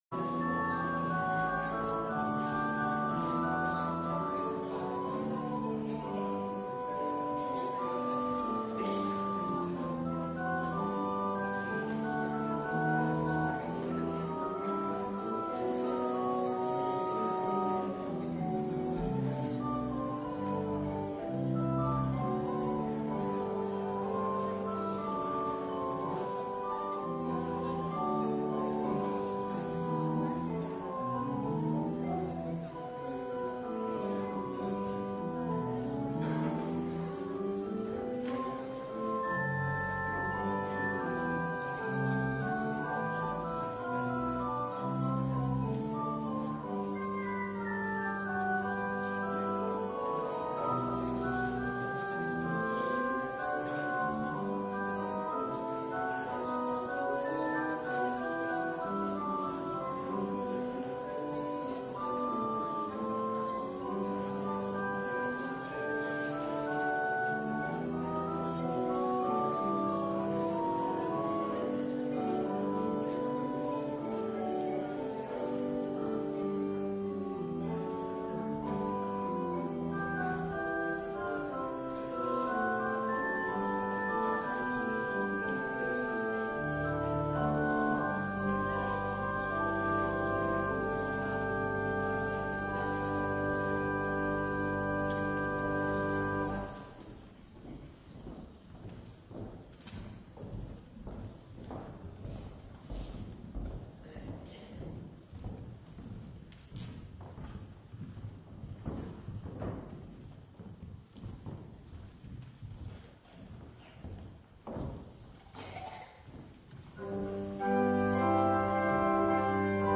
Wees moedig – Dorpskerk Abbenbroek